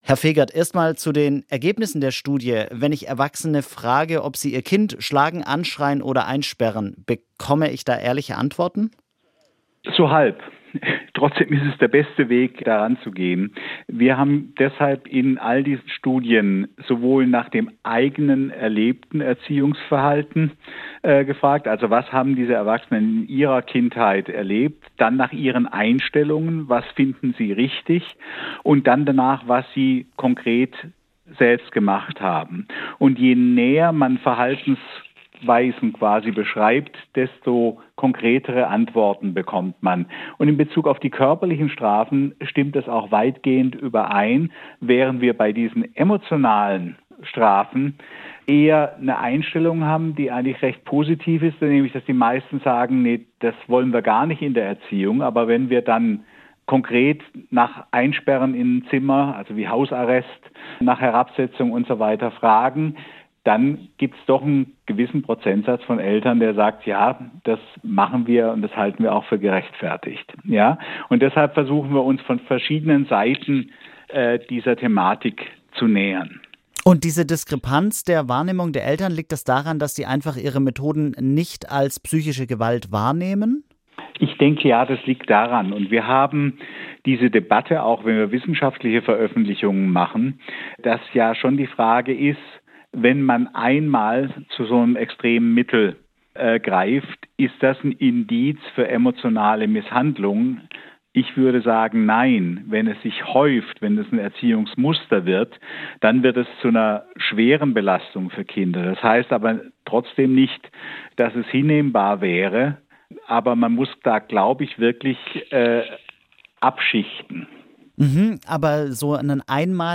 Welchen Unterschied das macht wie sich die eigene Kindheit auf den Erziehungsstil auswirkt, darum geht es im Gespräch